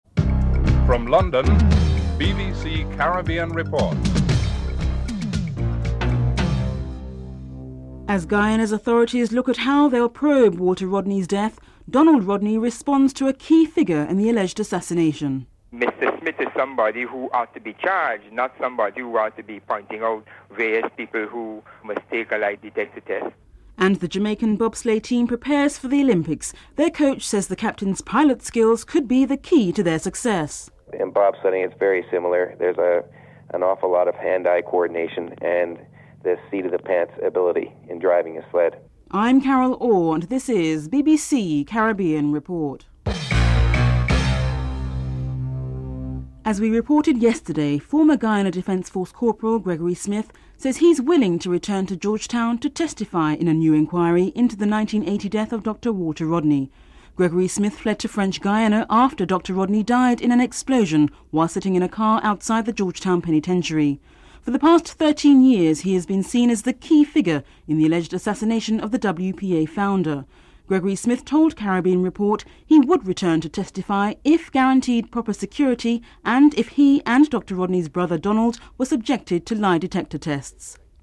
1. Headlines (00:00-00:45)